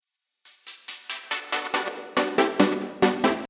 最後の方がミョワーンとなっているかと思います。